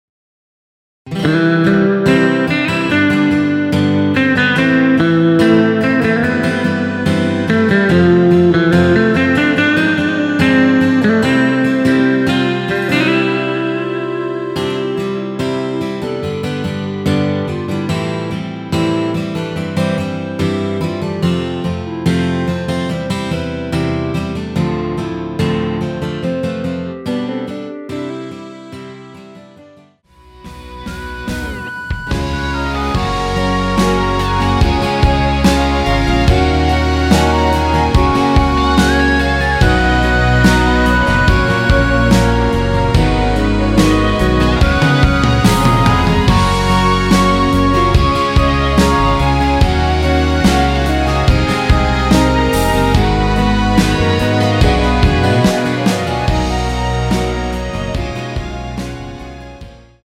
원키에서(-1)내린 멜로디 포함된 MR입니다.(미리듣기 확인)
노래방에서 노래를 부르실때 노래 부분에 가이드 멜로디가 따라 나와서
앞부분30초, 뒷부분30초씩 편집해서 올려 드리고 있습니다.